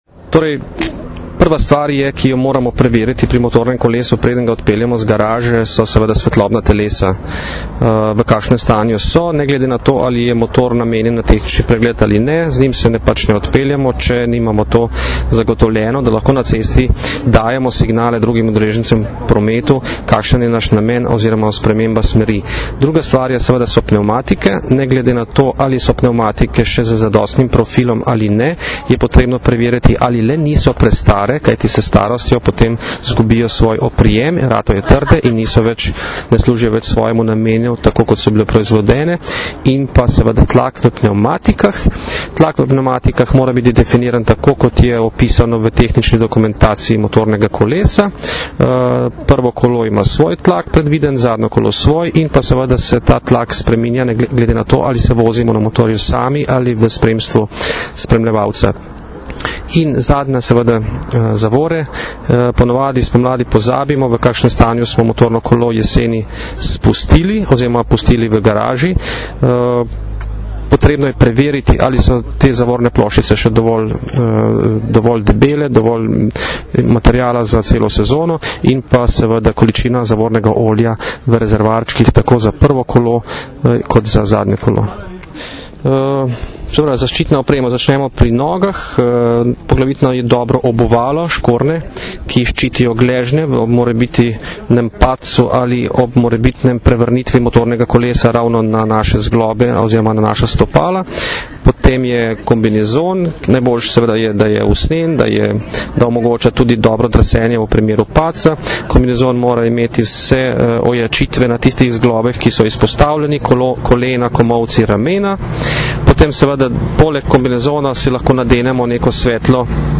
Naj bo motoristična sezona prijetna in varna - informacija z novinarske konference
Zvočni posnetek izjave